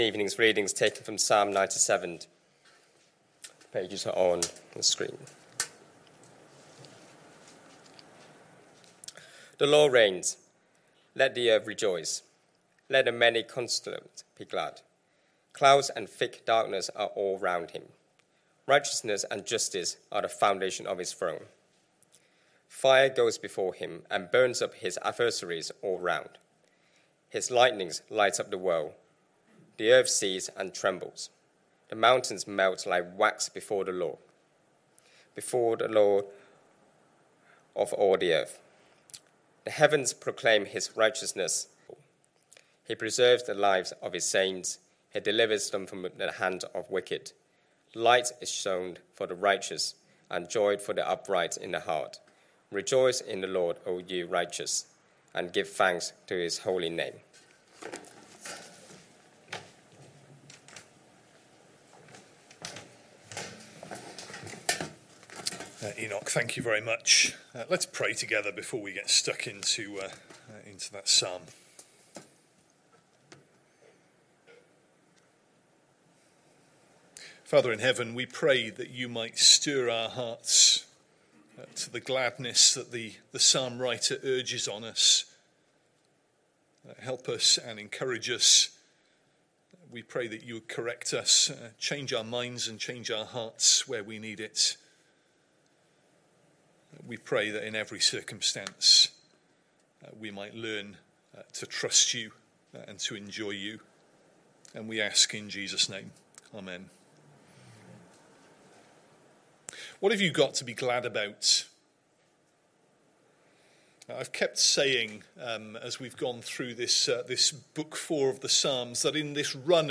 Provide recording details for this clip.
A message from the series "Psalms." Sunday PM Service Sunday 2nd November 2025 Speaker